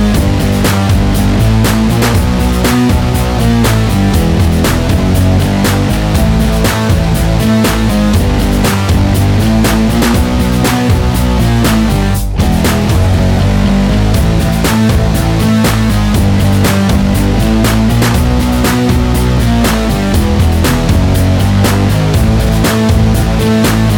no Backing Vocals Indie / Alternative 3:30 Buy £1.50